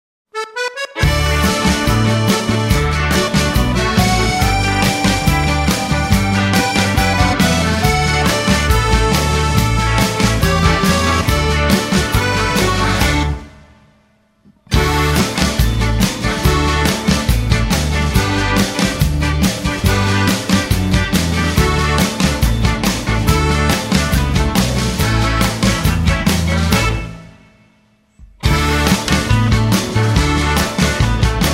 ▪ The full instrumental track